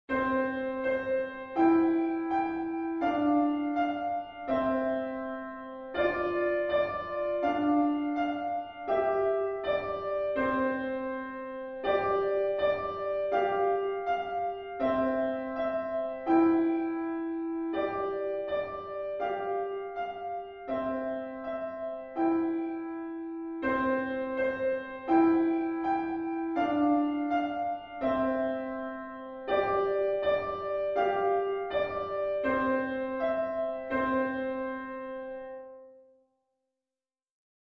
リピートは基本的に省略していますが、D.C.を含むものは途中のリピートも全て再現しています